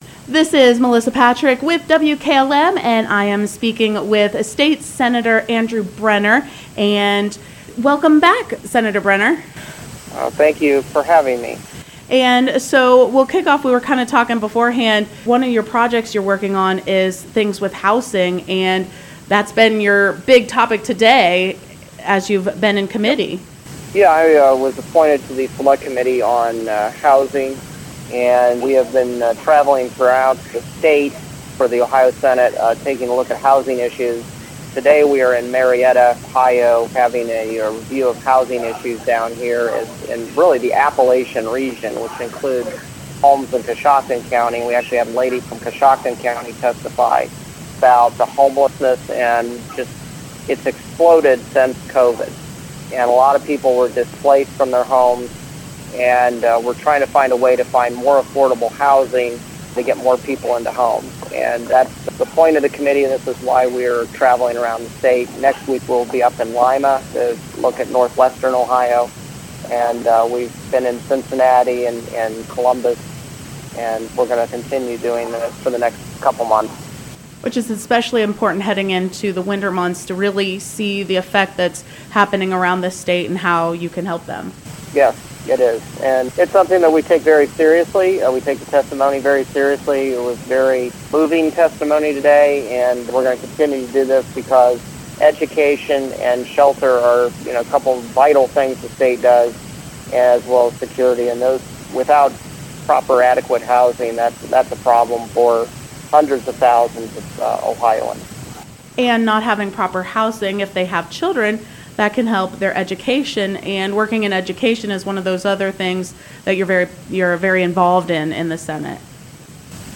12-5-23 Interview with Ohio State Senator Andrew Brenner